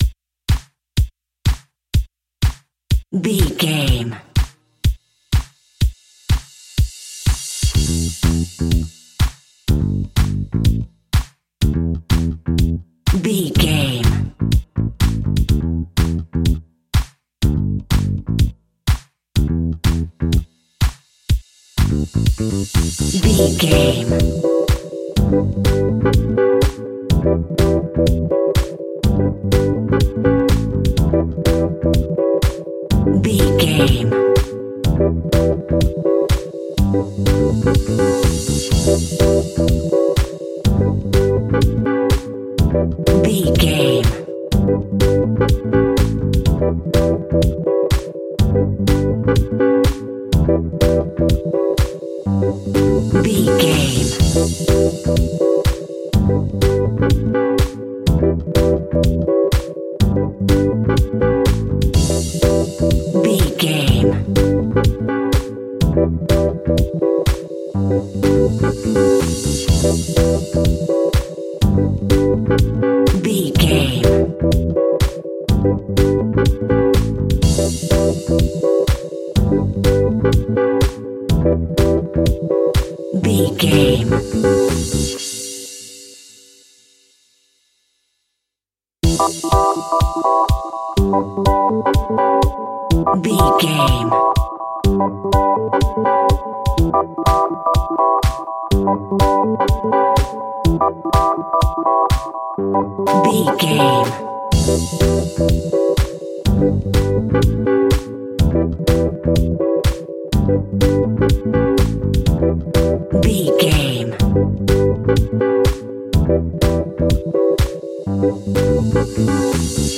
Groove Soul Disco House Alt.
Aeolian/Minor
groovy
uplifting
energetic
funky
bass guitar
drums
electric piano
synthesiser
funky house
nu disco
upbeat